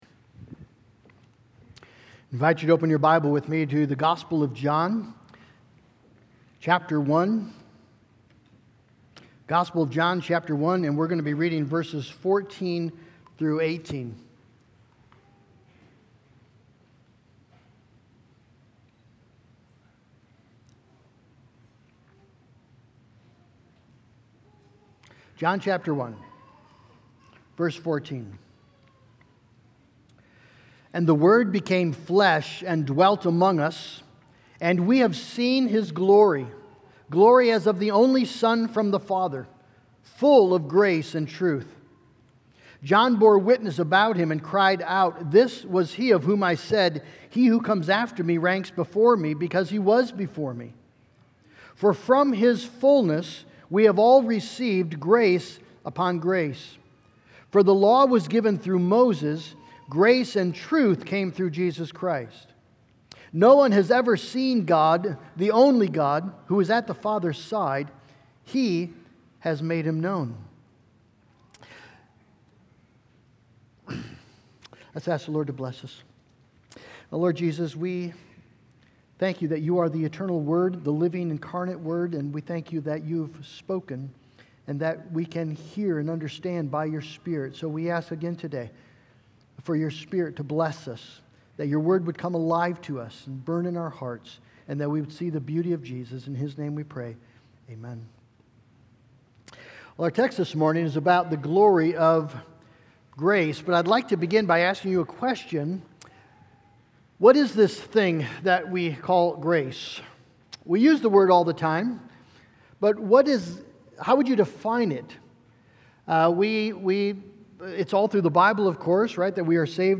Sermons & Teaching